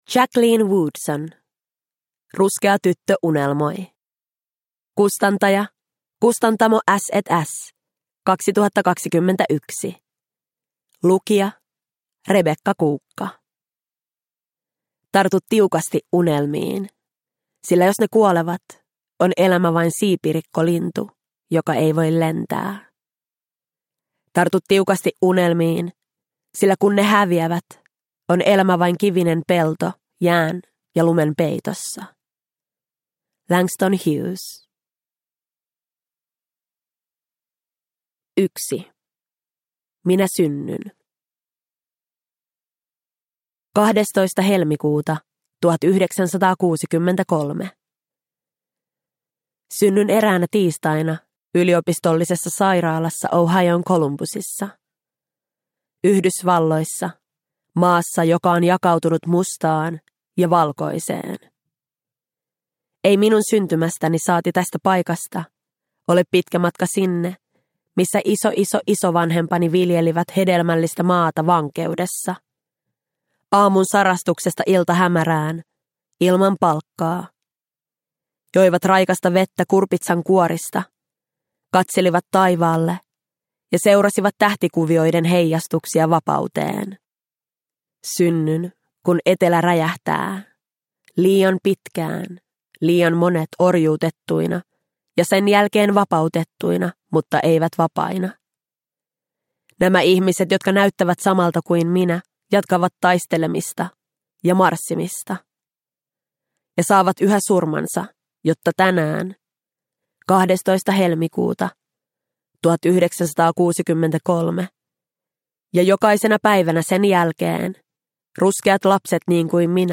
Ruskea tyttö unelmoi – Ljudbok – Laddas ner